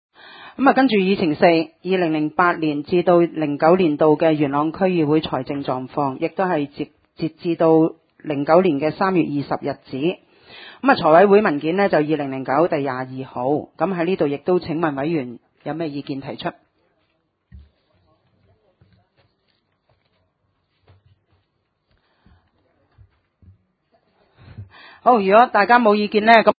點： 元朗區議會會議廳